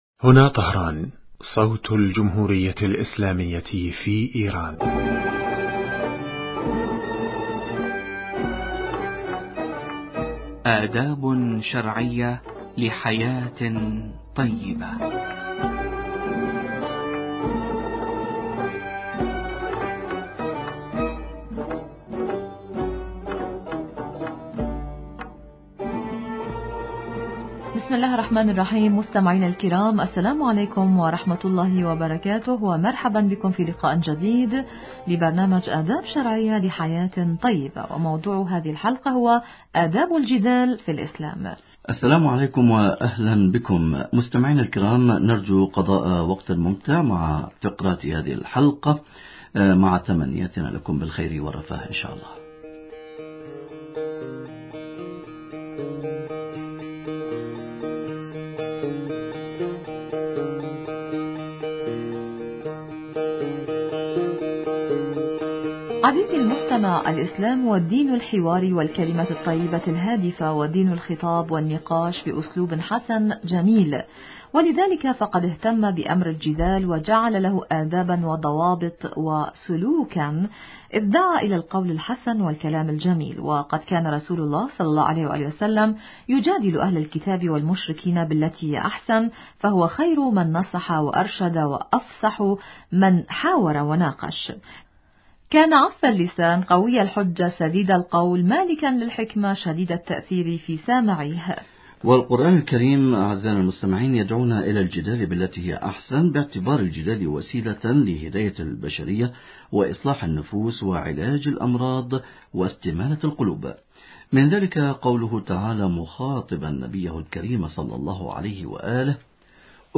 ضيف البرنامج